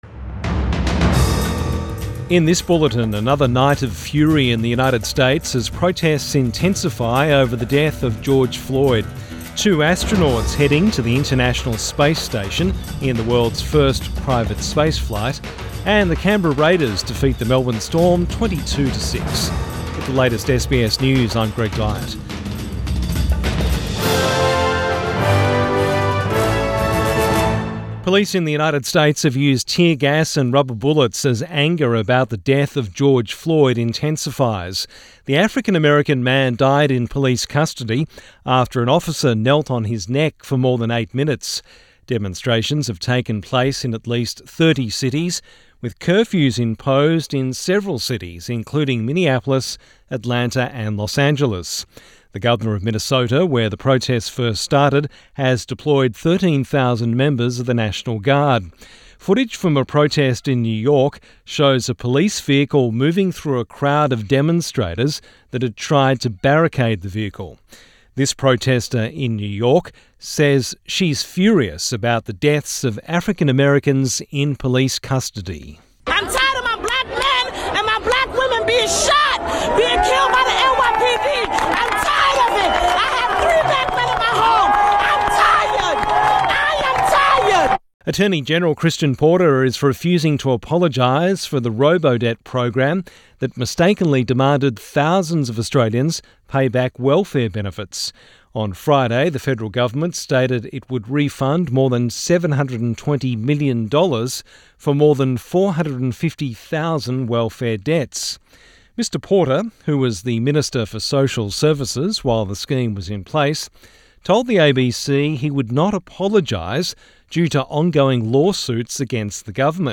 PM bulletin 31 May 2020